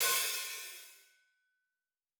TC2 Live Hihat3.wav